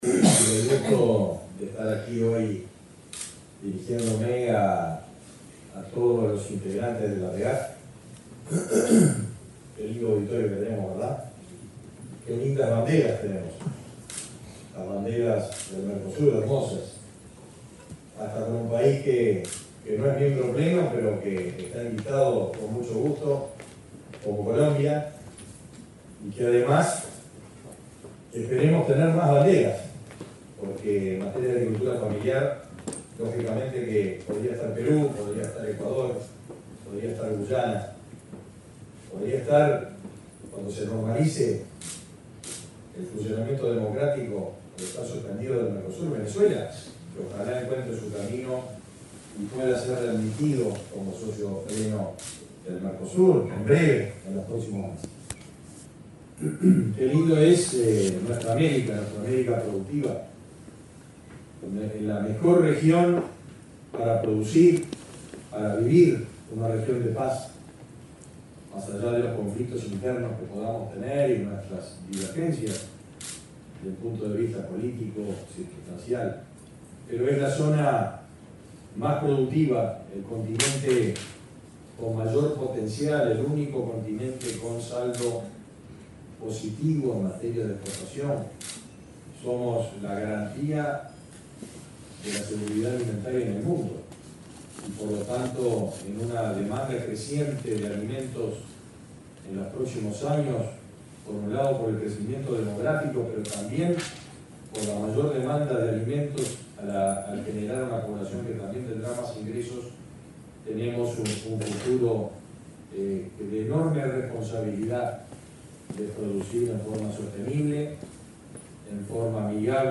Palabras del ministro de Ganadería, Fernando Mattos
El ministro de Ganadería, Fernando Mattos, participó, en el acto de cierre de la XLI Reunión Especializada de Agricultura Familiar, realizada este